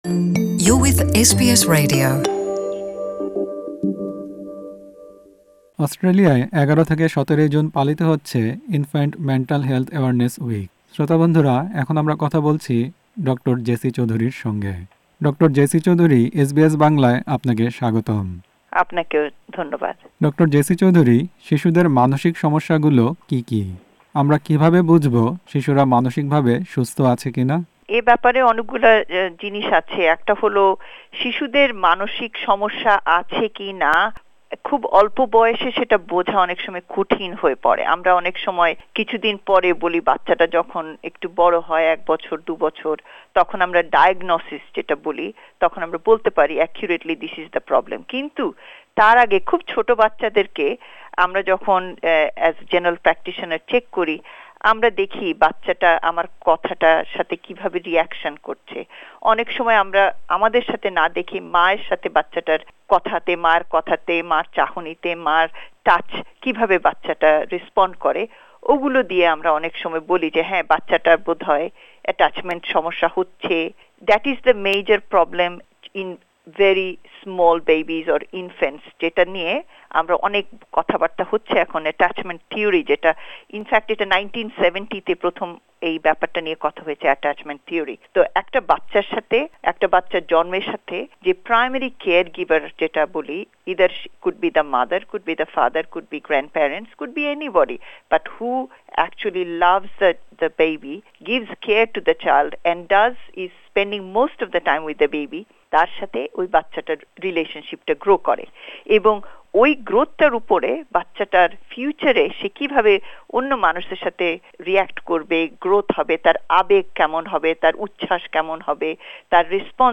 সাক্ষাত্কার